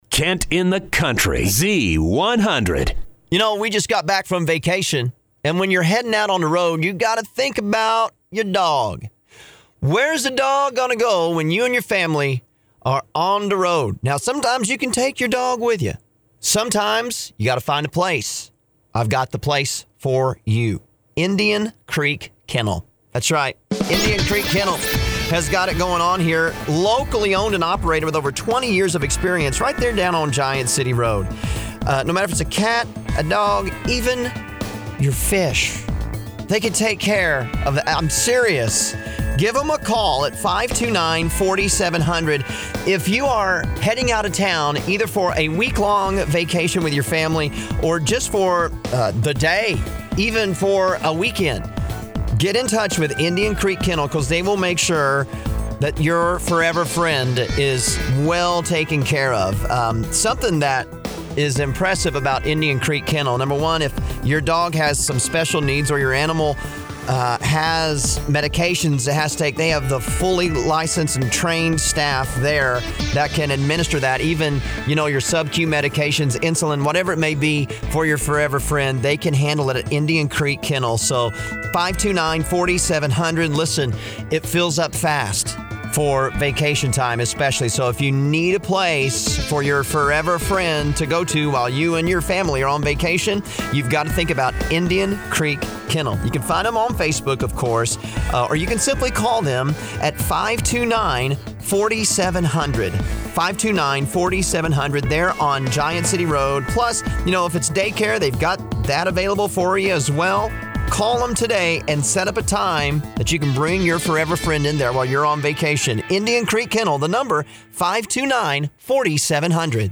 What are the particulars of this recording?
Indian-Creek-Kennel-LIVE-WOOZ-6-18-19.mp3